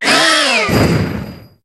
Cri de Matoufeu dans Pokémon HOME.